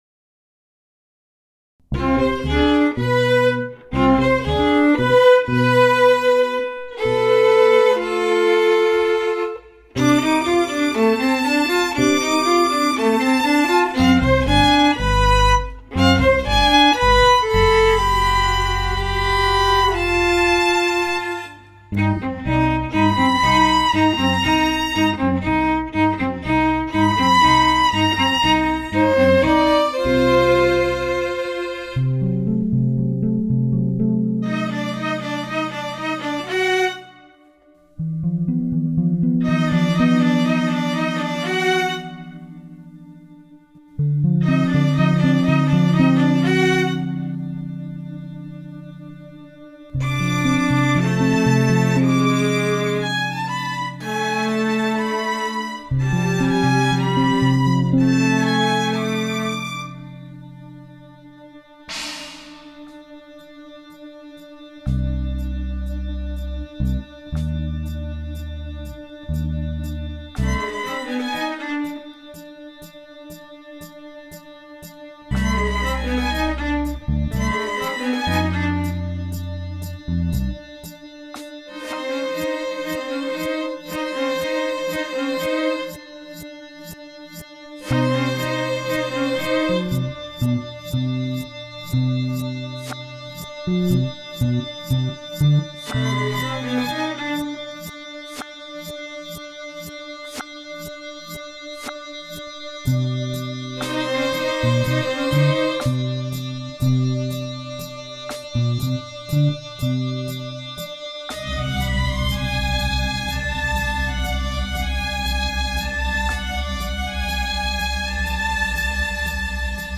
Violin and viola
The structure is quite symmetrical, comprising five one-minute sections proceeding at a consistent120 beats per minute.
Sections 1 and 2 focus on violin, viola and bass guitar. They use a symmetrical scale, where the intervals between notes follow the pattern whole tone / semitone / whole tone / semitone / etc. This is called the “diminished scale” or “Messiaen’s second mode of limited transposition”.
Section 3 continues with time-stretched violin and viola drones and bass guitar.
We used the Physics Teaching Laboratories, which have a nice bright echo and some bass resonances (coming from cavities formed by the lab benches, we think). Fragments of the original voices are mixed into the room resonance along with some vocoder, a voice treatment gaining popularity in the same decade.
Tones for section 5 were synthesised using pure sine waves in MATLAB, and also approximated on (fretless) bass guitar.
Spontaneous symmetry breaking is an important idea in physics, and so right at the end of section 5 the symmetrical scale and 60-TET rules are abandoned in favour of a nice ordinary chord!